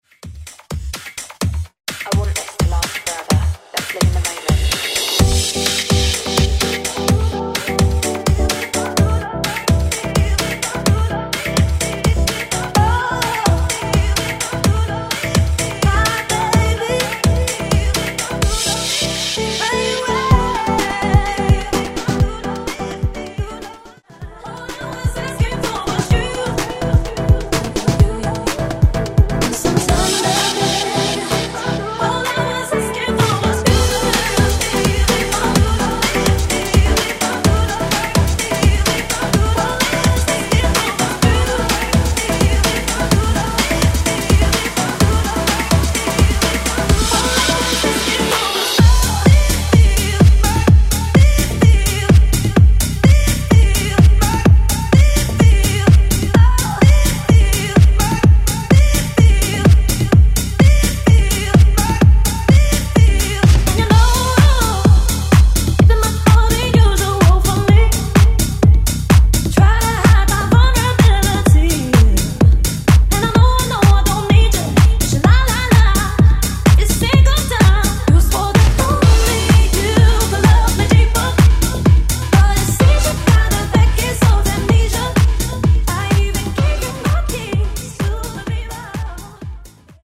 Genre: 70's
Clean BPM: 135 Time